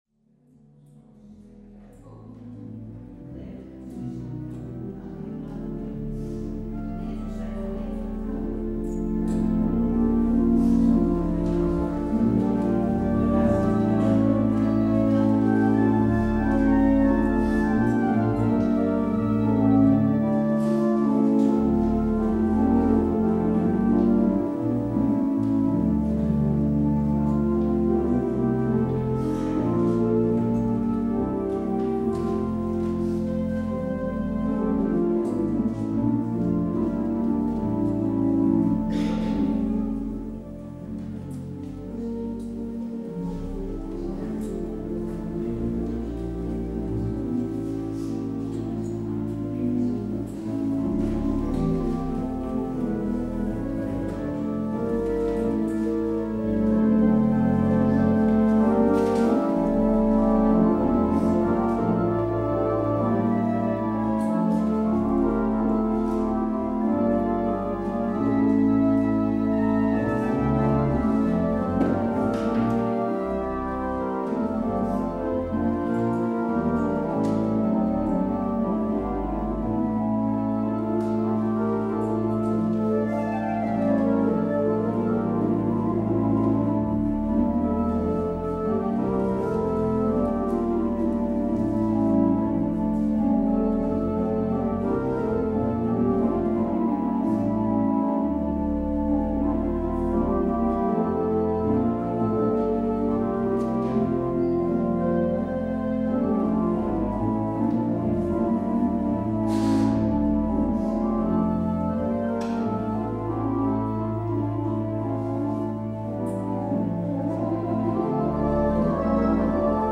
Het openingslied is Psalm 72: 1 en 4.
Als slotlied hoort u Gezang 26:1 en 3.